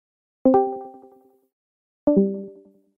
Звуки для монтажа видео